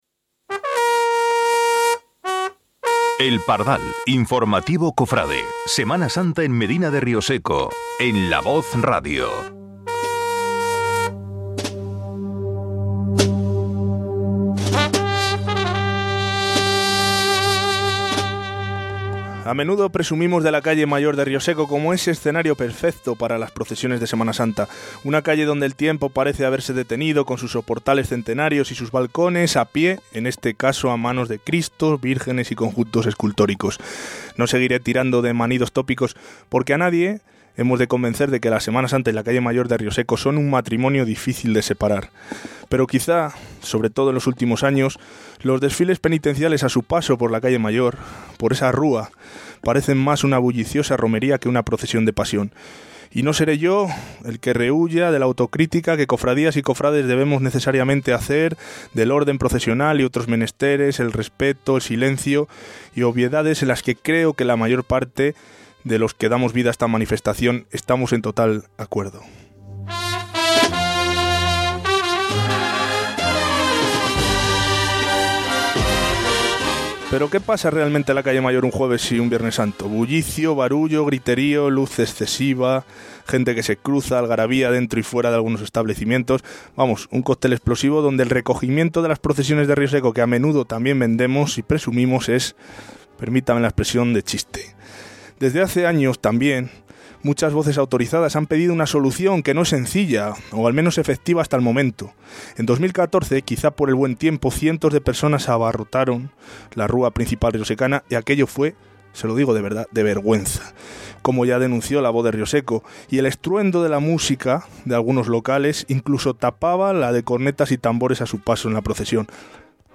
Por los micrófonos de La Voz Radio han pasado los emocionados testimonios de algunos de sus amigos